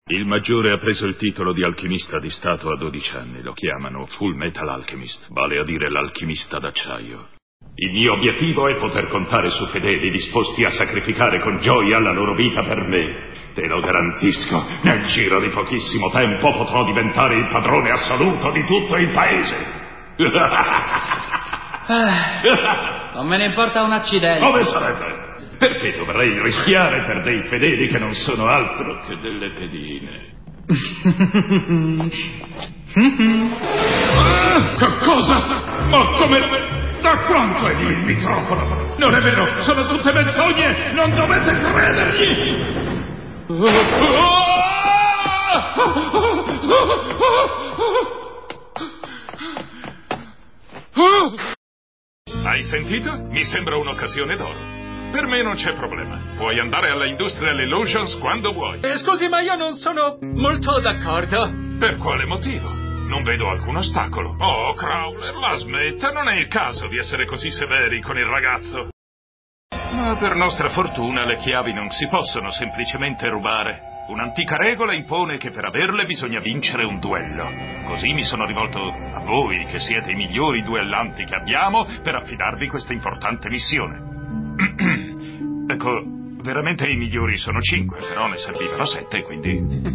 in alcuni estratti dai cartoni animati "FullMetal Alchemist